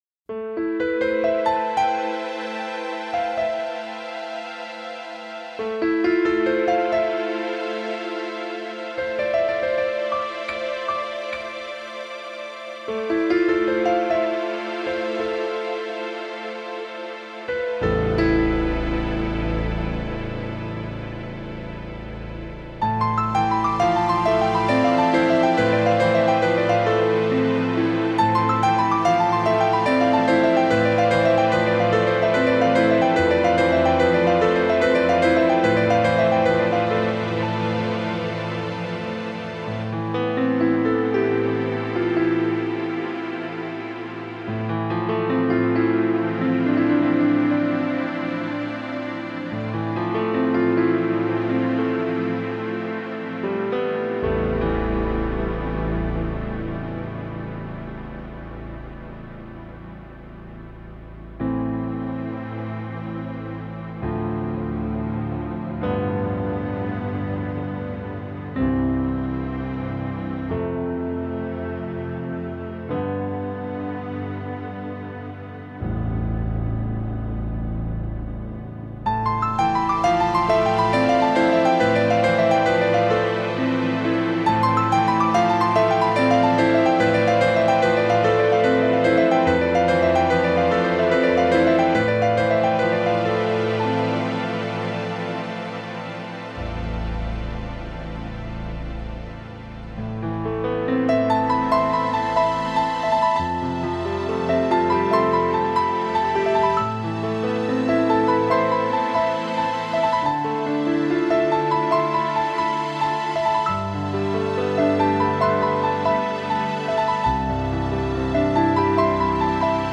新世紀音樂/鋼琴
上傳之音輯僅低音質壓縮，請會員於下載試聽後即刪除檔案，收藏敬請購買原版音碟．